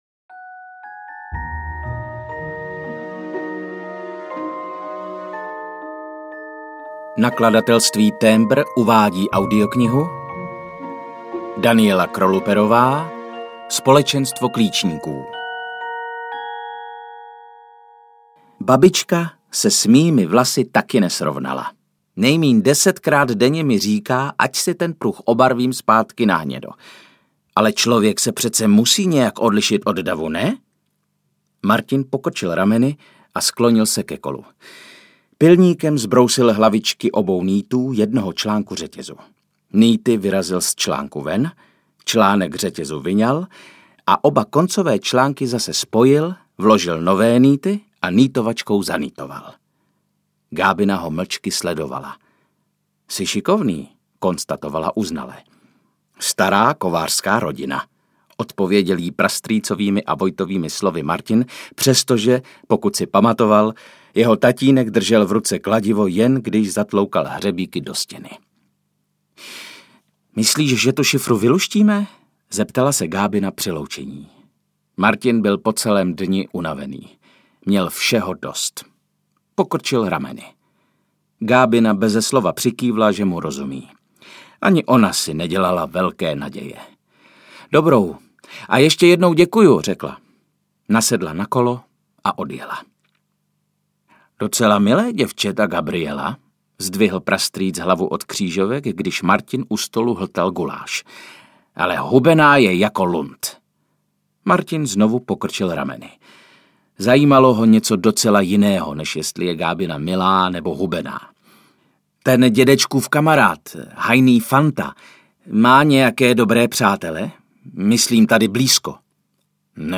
Společenstvo klíčníků audiokniha
Ukázka z knihy
• InterpretViktor Dvořák, Otakar Brousek ml.